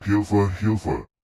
ggl_id-ID-Wavenet-C_-6.wav